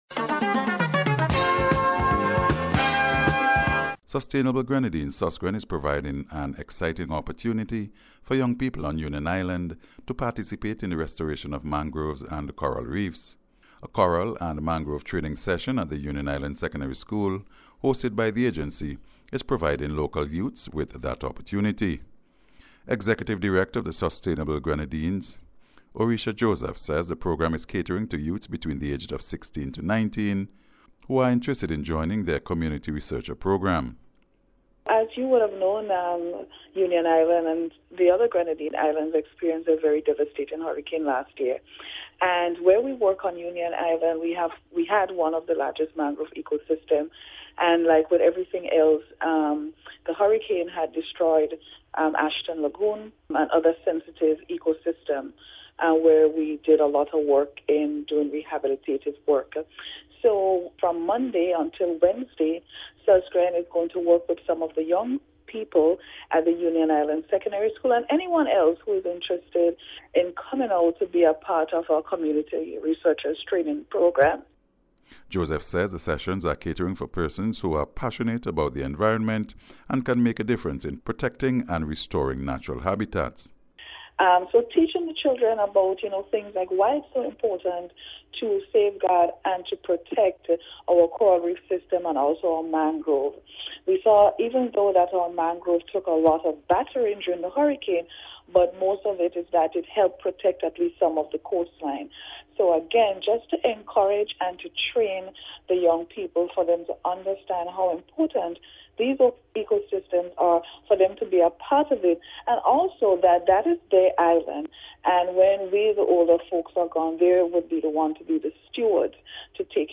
NBC’s Special Report- Tuesday 5th August,2025